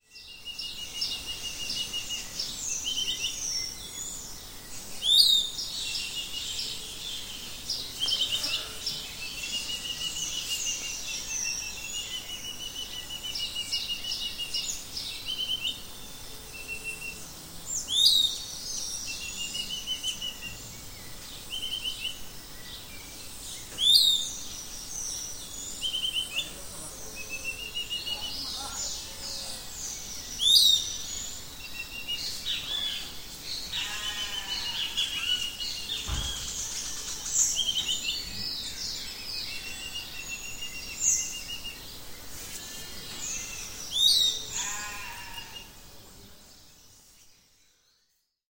Tuquito Chico (Legatus leucophaius)
Nombre en inglés: Piratic Flycatcher
Localización detallada: Reserva San Rafael (procosara)
Condición: Silvestre
Certeza: Fotografiada, Vocalización Grabada
Legatus-leucophaius.mp3